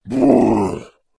Звуки великанов
Здесь собраны аудиозаписи, передающие шаги, голоса и атмосферу этих гигантских существ.